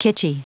Prononciation du mot kitschy en anglais (fichier audio)
Prononciation du mot : kitschy